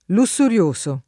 lussurioso [ lu SS ur L1S o ] agg.